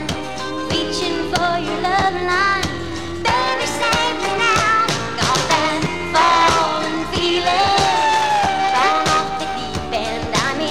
Reducing Fuzz and Lag in Vintage Music (Newbie)
I’m working on a vintage Canadian music restoration project, and a file was sent to me that has lag in play (it was recorded off a vinyl record that seems to have been made while other processes were running, causing the lag), and it gets very fuzzy at certain high and low points of the file.
It also sounds almost like it’s underwater - the audio is just off in general.